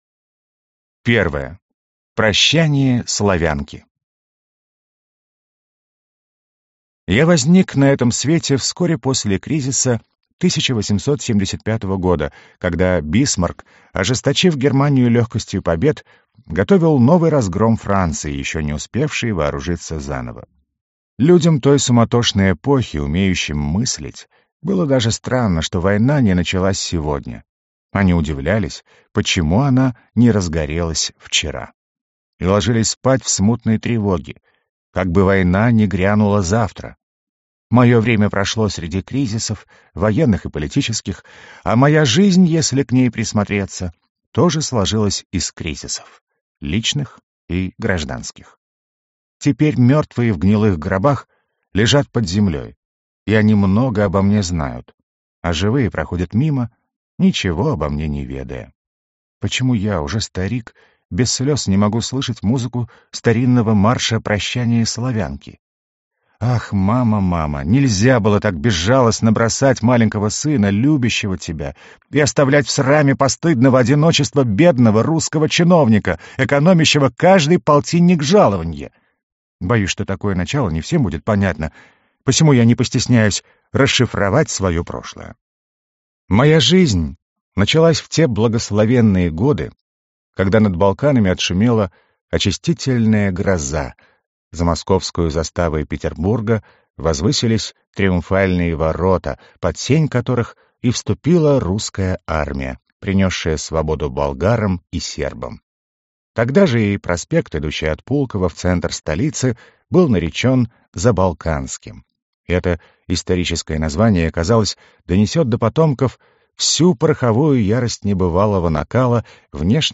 Аудиокнига Честь имею.